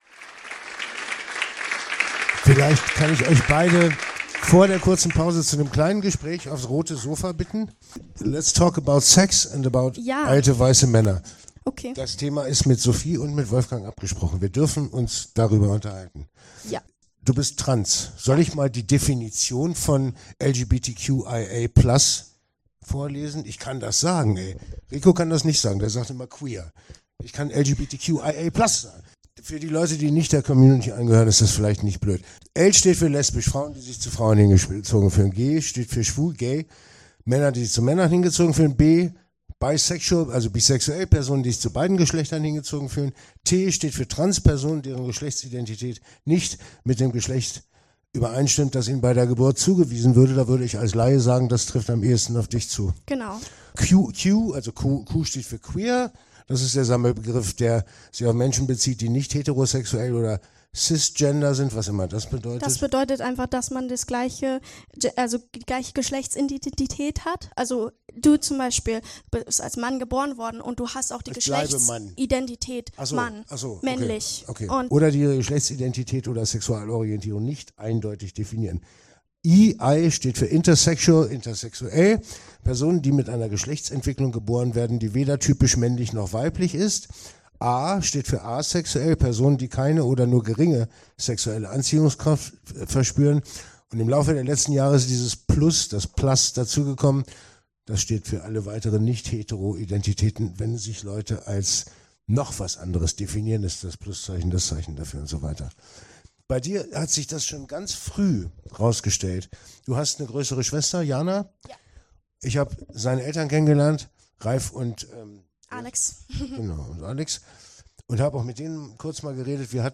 Kultur